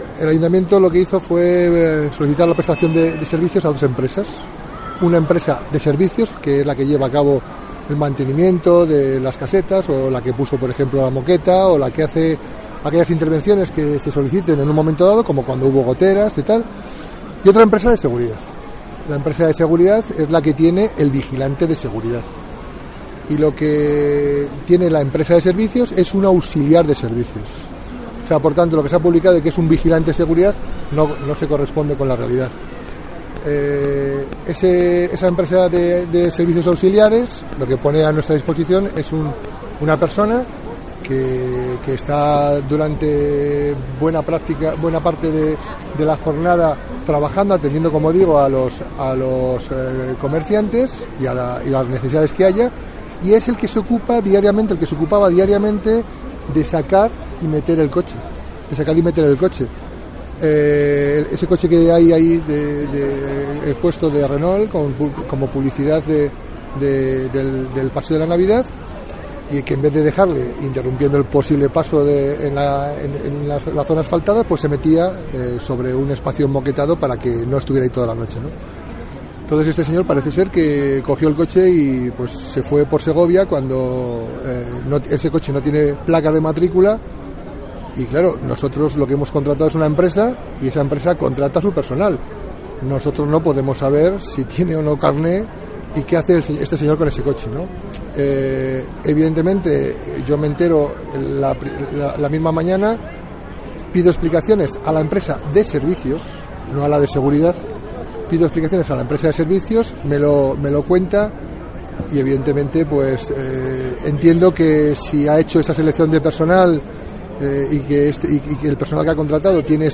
José Mazarías, alcalde de Segovia, sobre los servicios auxiliares del Paseo de la Navidad